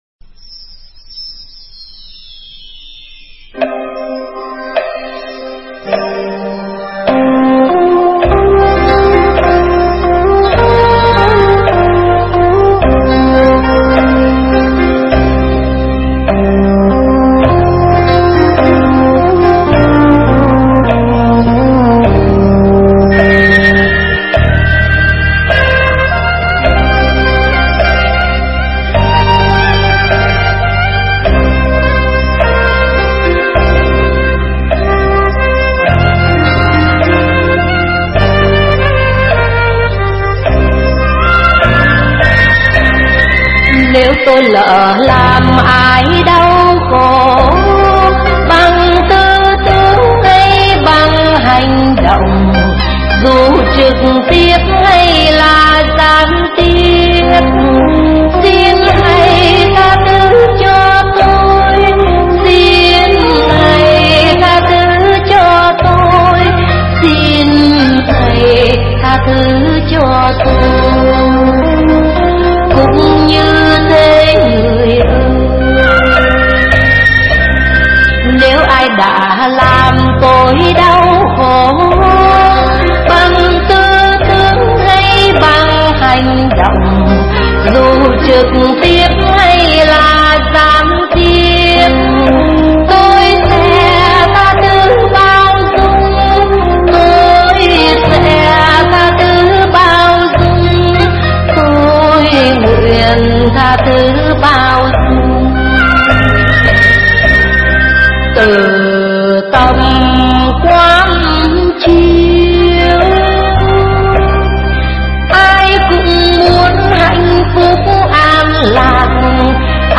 thuyết giảng tại Tu Viên Tây Thiên, Canada, khóa tu mùa xuân